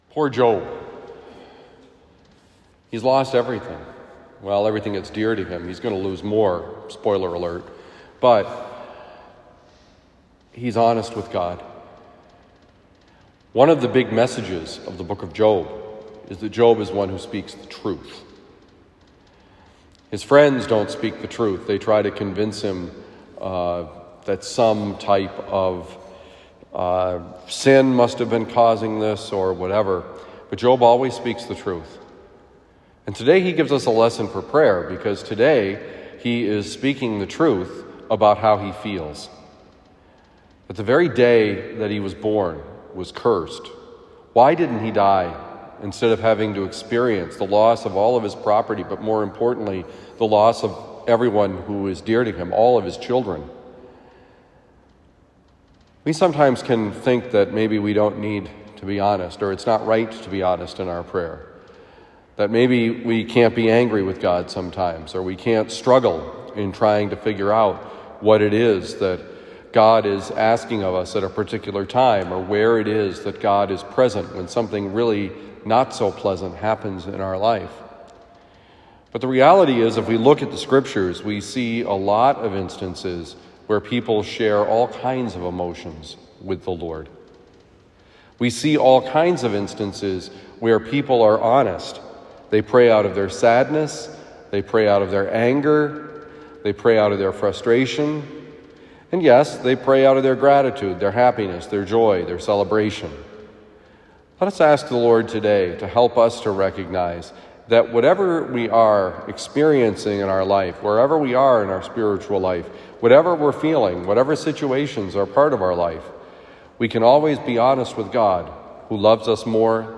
Don’t Fear Honesty with God: God Knows Who You Are: Homily for Tuesday, September 27, 2022
Given at Christian Brothers College High School, Town and Country, Missouri.